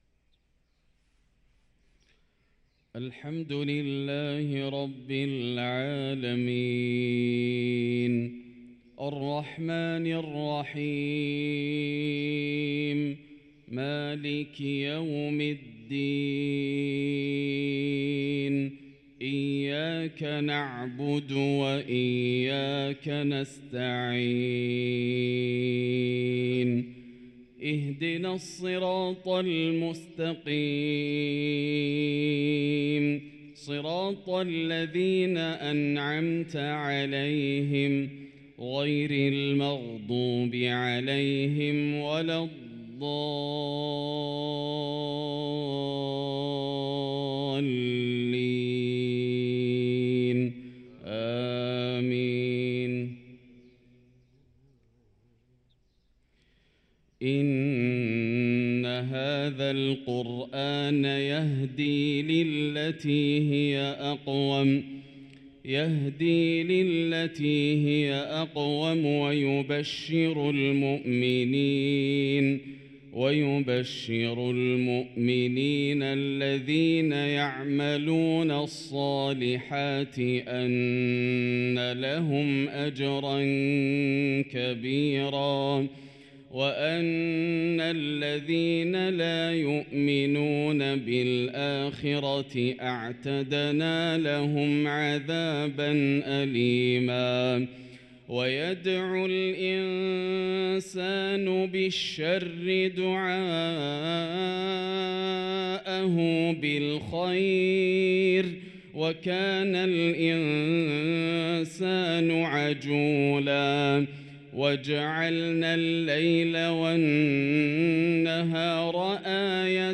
صلاة الفجر للقارئ ياسر الدوسري 10 صفر 1445 هـ
تِلَاوَات الْحَرَمَيْن .